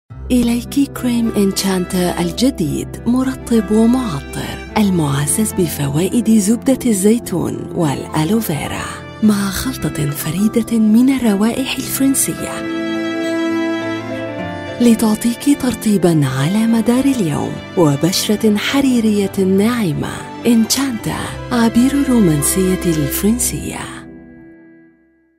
Native speaker Female 30-50 lat
Nagranie lektorskie